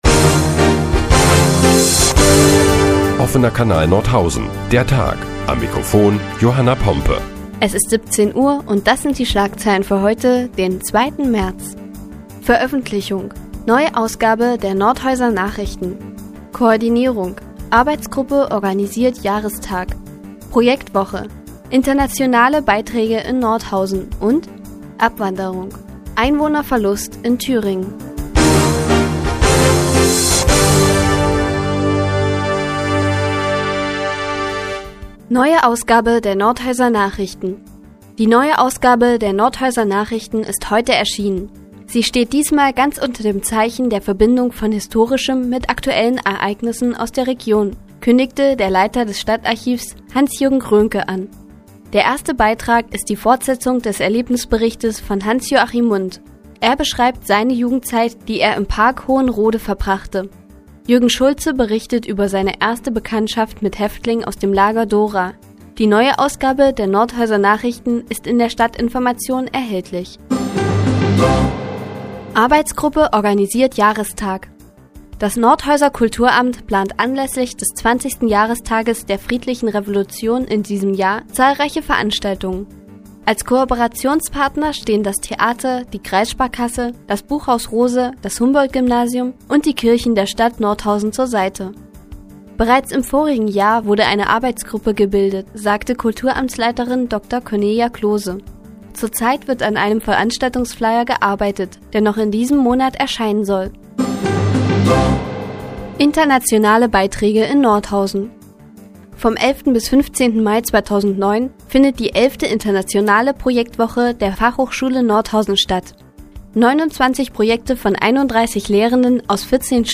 Die tägliche Nachrichtensendung des OKN ist nun auch in der nnz zu hören. Heute geht es unter anderem um die Projektwoche der Nordhäuser Fachhochschule und den Einwohnerverlust in Thüringen.